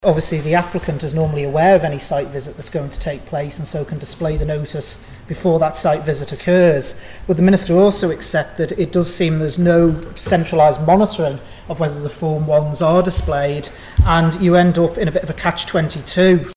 That's according to Douglas North MHK David Ashford who says he's concerned that the yellow site forms aren't always on show for the specified periods: